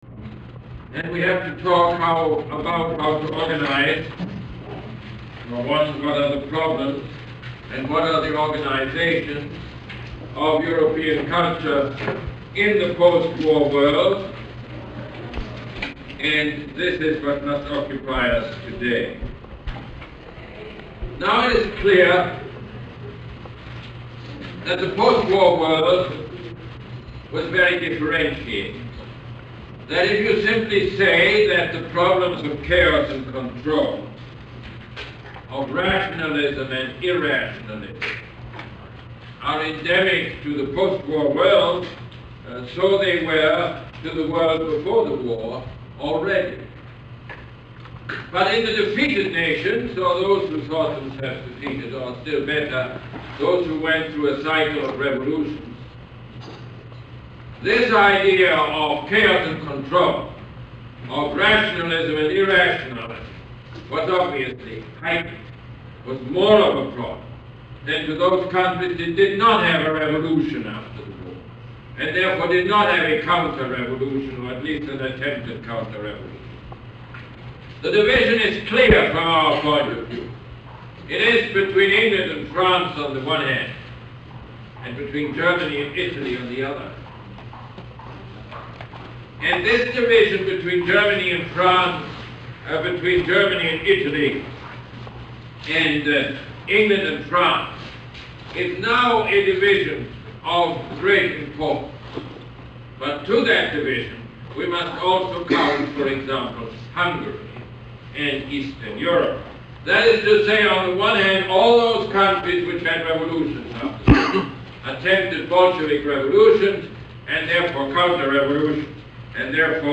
Lecture #21 - November 19, 1979